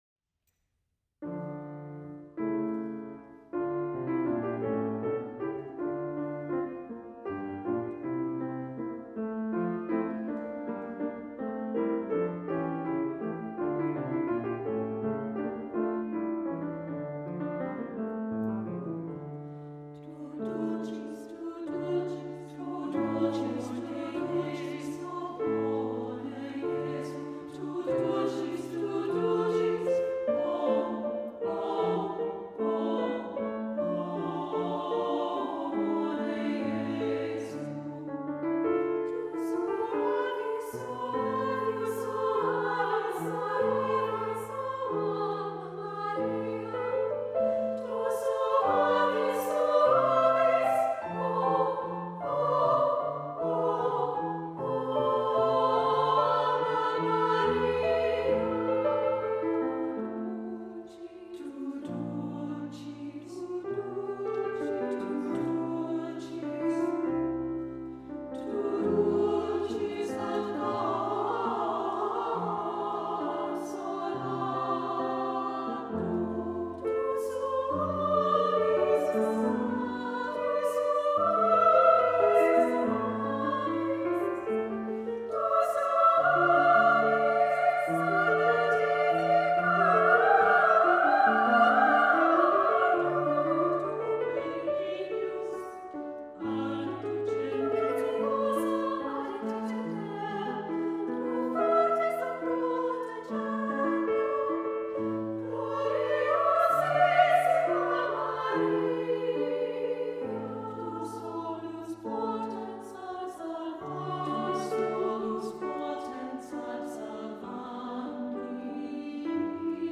SSAA, keyboard, 6 1/2 minutes